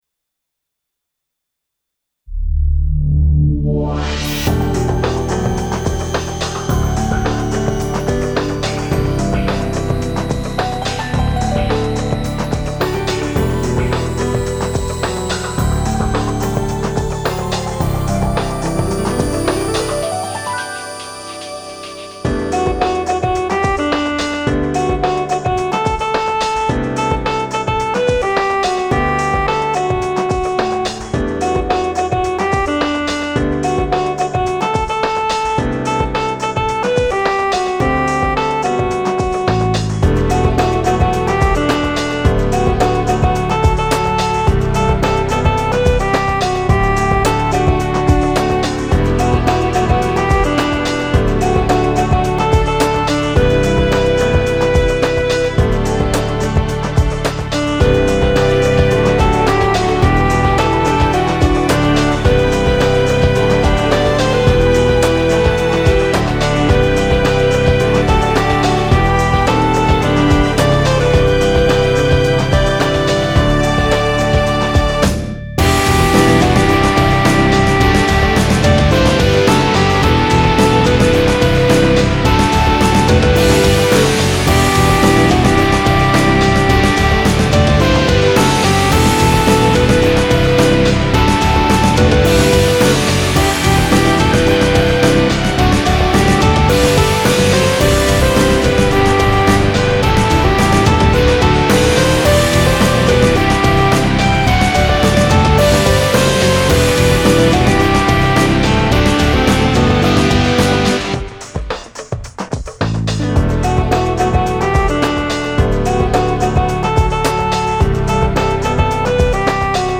試聴曲の音源